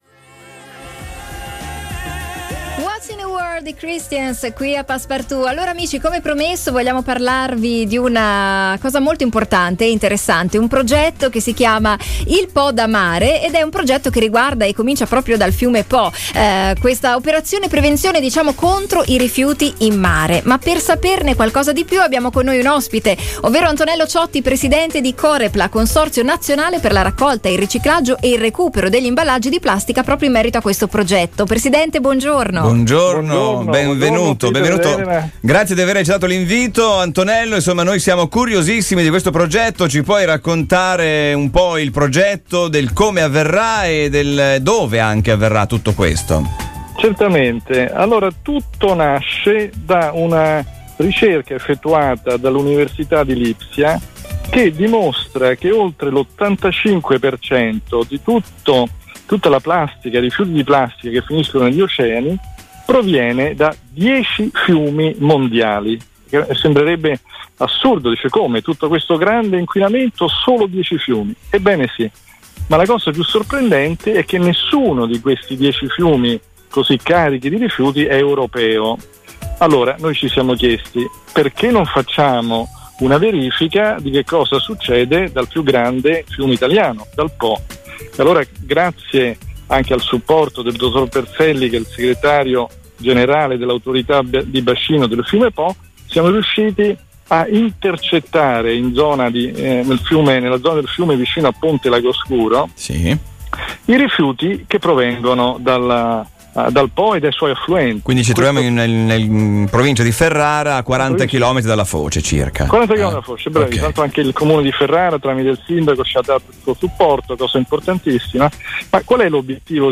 A spiegarci la tecnica utilizzata e gli scopi